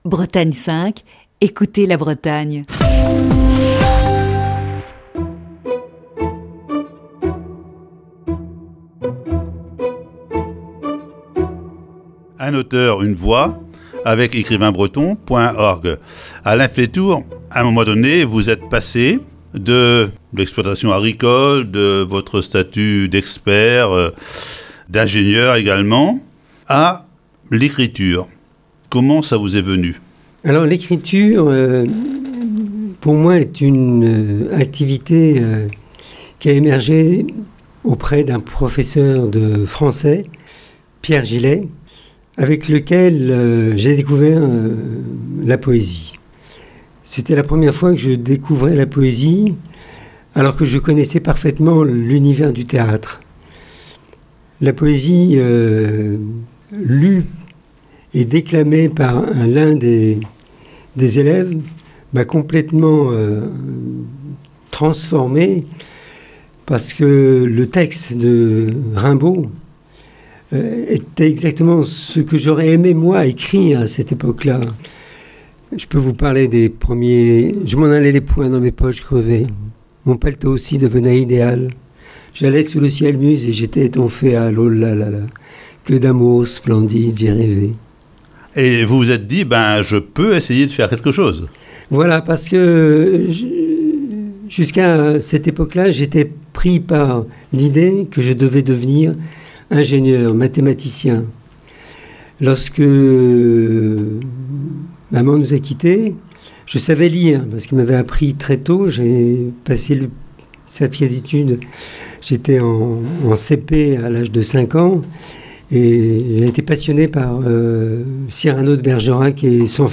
série d'entretiens